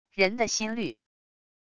人的心率wav音频